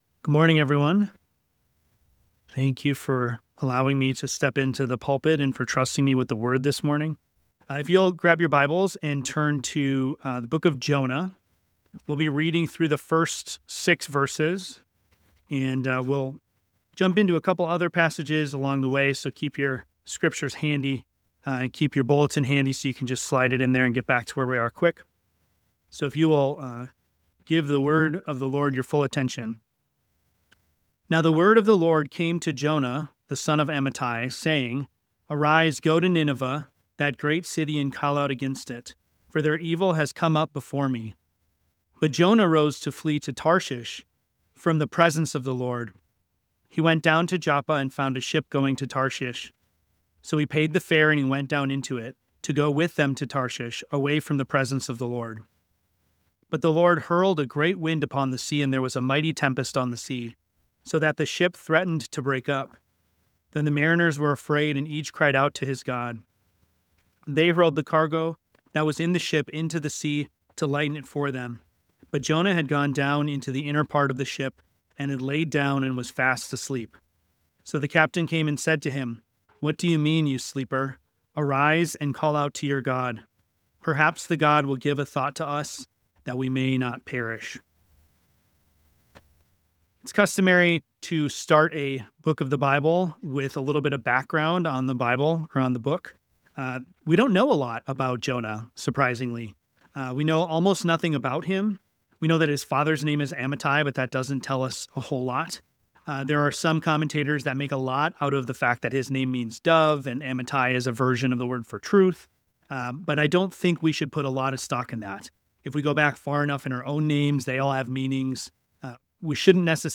The sermon delves into the sovereign presence of God, showing how His omnipotence and omnipresence are evident even in Jonah’s attempts to escape.